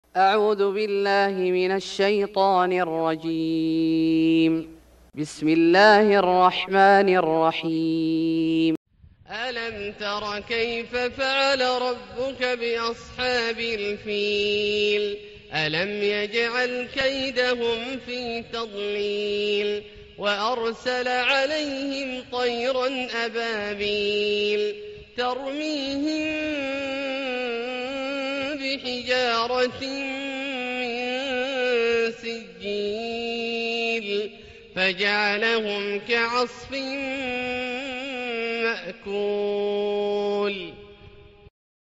سورة الفيل Surat Al-Fil > مصحف الشيخ عبدالله الجهني من الحرم المكي > المصحف - تلاوات الحرمين